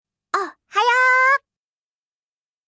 📌 Casual & Playful → A shortened and more playful version of “Ohayogozaimasu!” Commonly used among friends or in informal situations.